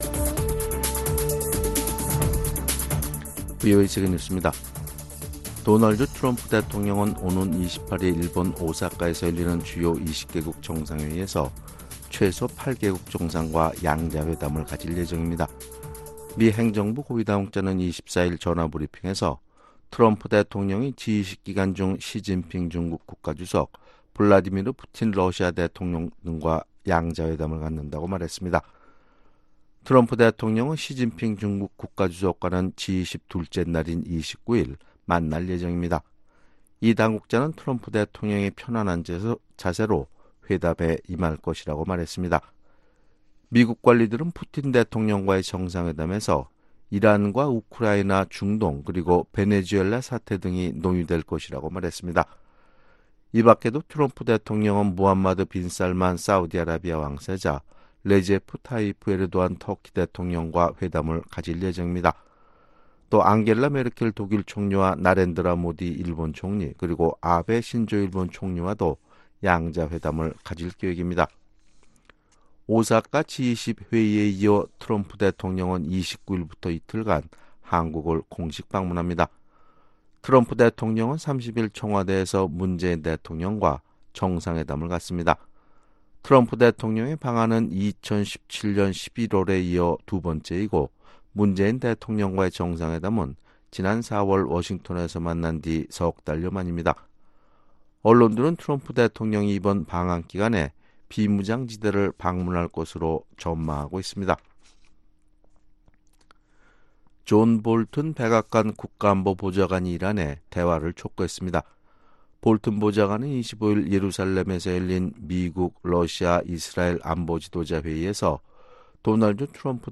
VOA 한국어 아침 뉴스 프로그램 '워싱턴 뉴스 광장' 2019년 6월 26일 방송입니다. 북한 김정은 국무위원장은 비핵화에 대한 준비가 돼 있지 않은 것으로 보고 있다고 미국 국방정보국 (DNI) 국장이 밝혔습니다. 트럼프 대통령이 이번 주말 한국 방문 중 비무장지대 DMZ를 방문하는 방안이 검토되고 있는 가운데, 미국의 일부 언론들은 트럼프 대통령이 방한을 계기로 김정은 위원장과의 만남을 준비할 수도 있다고 보도했습니다.